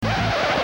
scratch3.mp3